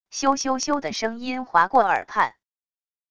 咻咻咻的声音划过耳畔wav音频